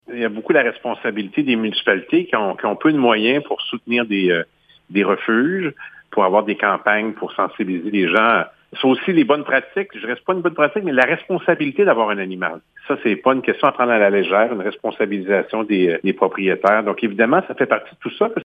Le député de Matane-Matapédia, Pascal Bérubé, aborde le sujet de l’augmentation des animaux errants :